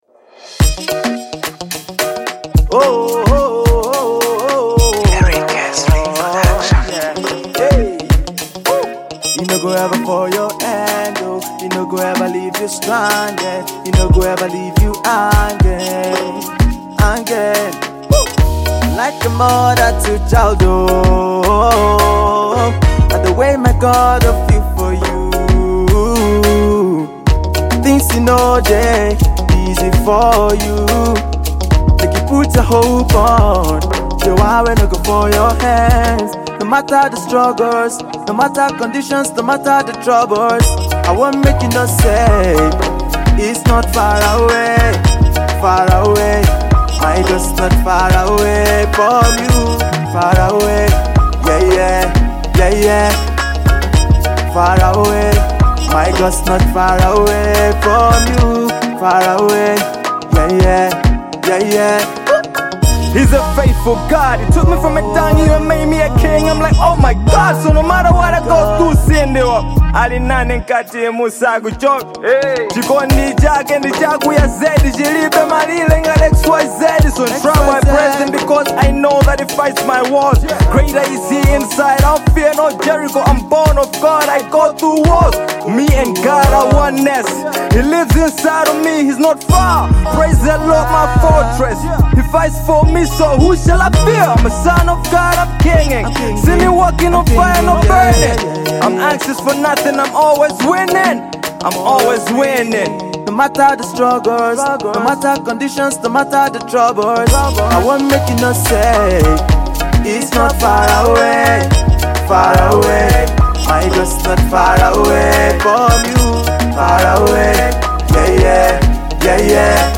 The song is tuned in Afro-pop beat